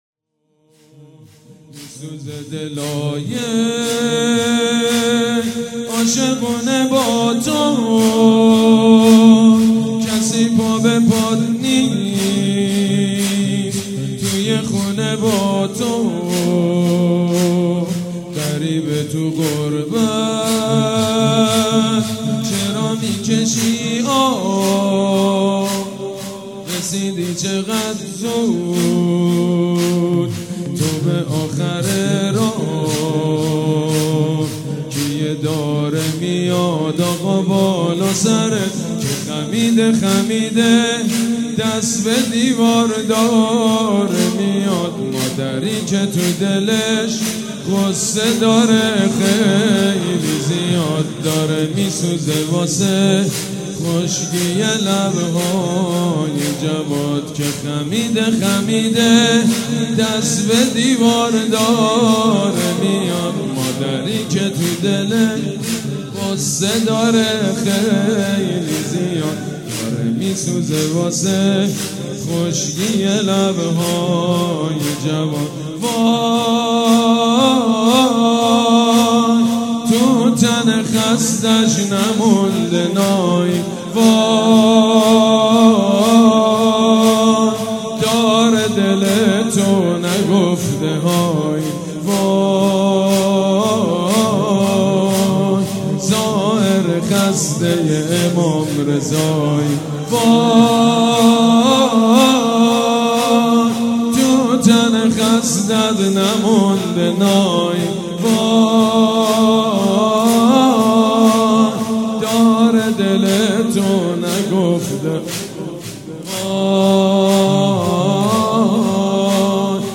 «شهادت امام جواد 1394» زمینه: میسوزه دلای عاشقونه با تو